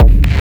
• Analog Crispy Distorted Techno One Shot Kick.wav
Thumpy Distorted Techno kick, Berlin techno kick.
Analog_Crispy_Distorted_Techno_One_Shot_Kick_LQy.wav